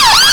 Index of /server/sound/vehicles/lwcars/sfx/sirens
uk_yelp.wav